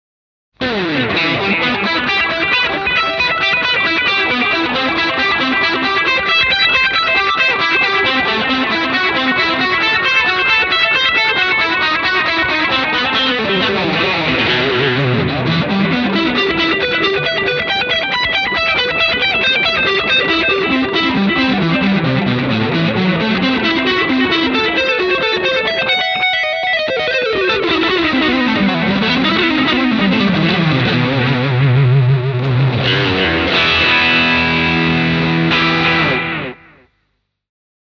Two different Distortion Blender - Bold Distortion
This effect has the mixed outputs of bold tone. Both inside Crunch Distortion and Heavy Distortion are mixed half-and-half.
Demo with SE-ADL Analog Delay